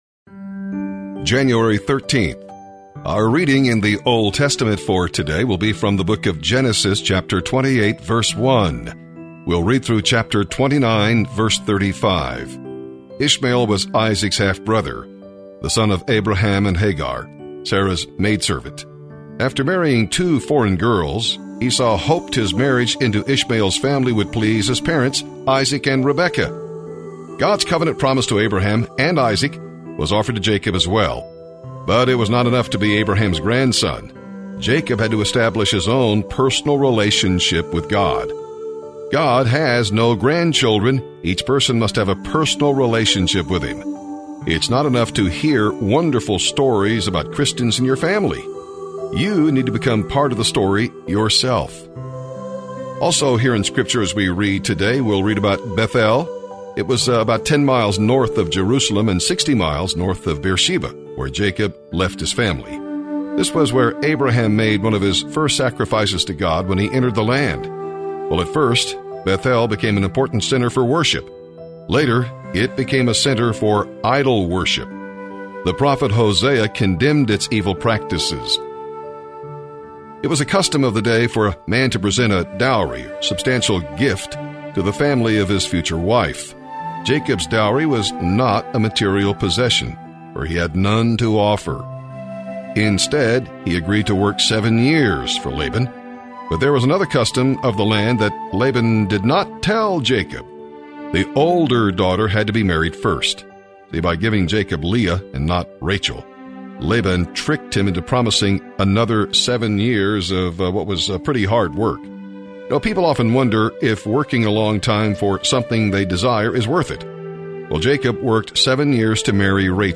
January 13th Bible in a Year Readings